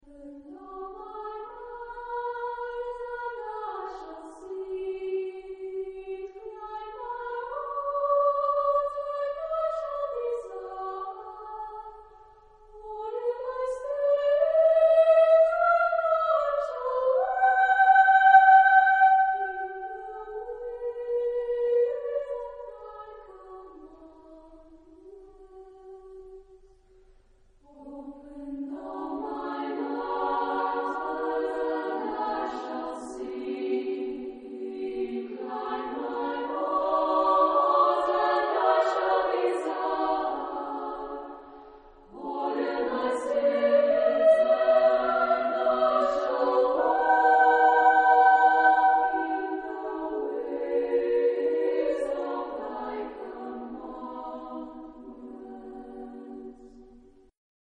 Genre-Style-Forme : Sacré
Type de choeur : SATB  (4 voix mixtes )